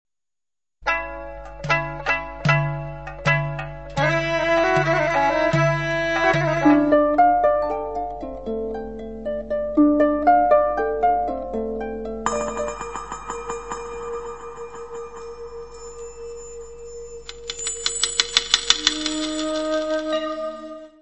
Descrição Física:  1 disco (CD) (39 min.) : stereo; 12 cm
Área:  Fonogramas Não Musicais